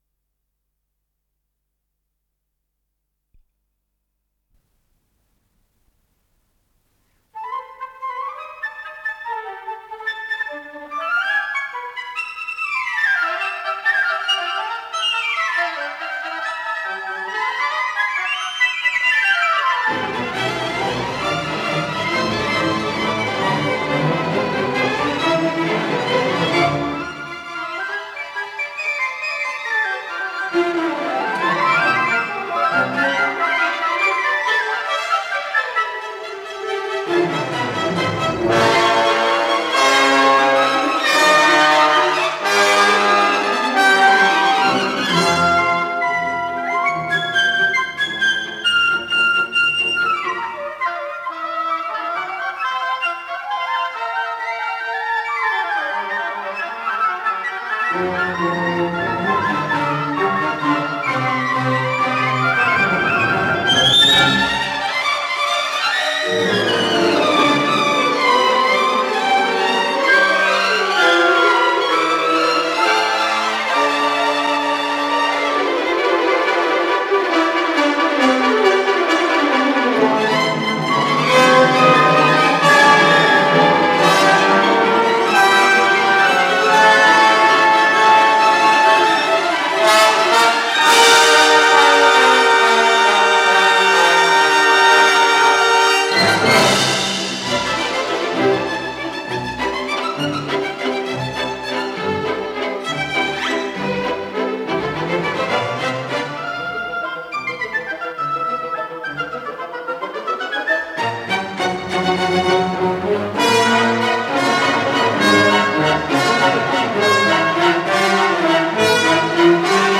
Скорость ленты38 см/с
ВариантМоно